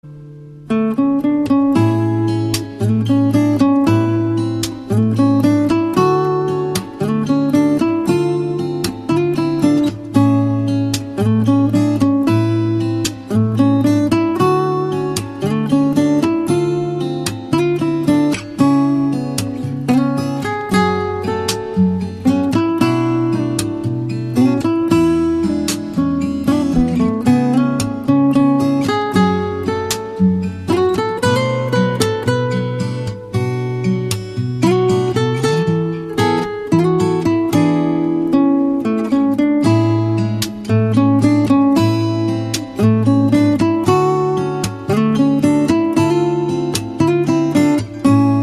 手机铃声